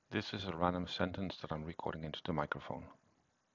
short_sentence.m4a